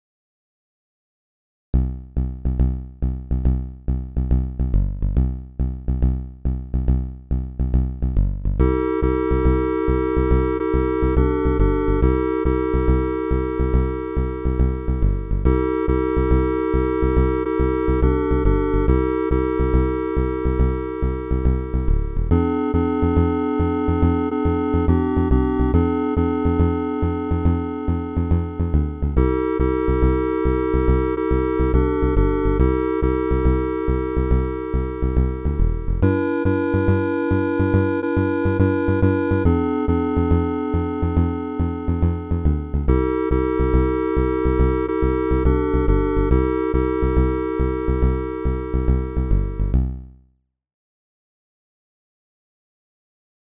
Early Synth-Wave Experiments